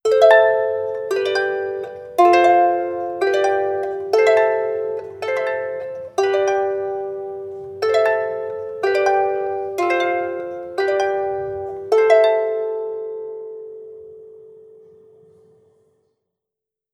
Original creative-commons licensed sounds for DJ's and music producers, recorded with high quality studio microphones.
celtic_harp_arpeggio_dyA.wav